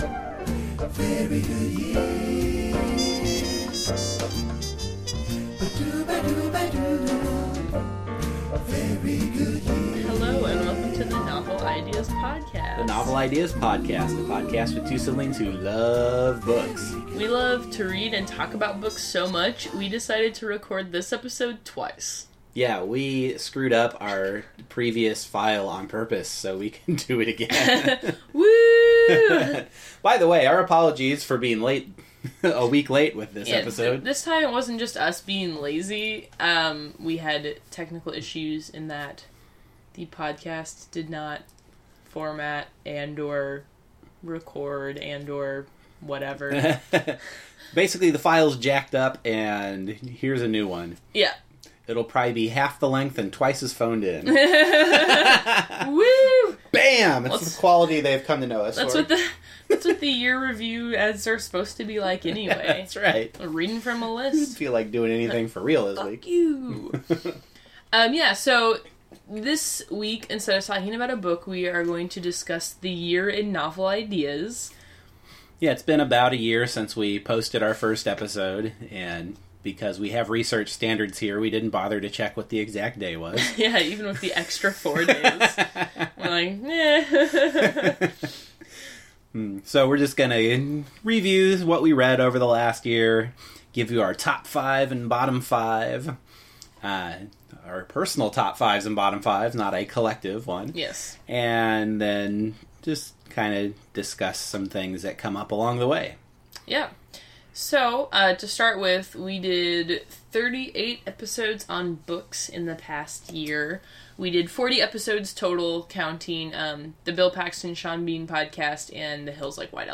We apologize for being a week late, but our recording from last week was corrupted somehow and we had to meet again and record the whole thing a second time. In this week’s episode, we cover our top and bottom five from the previous year, recount several old jokes, and even manage to make a couple of new ones.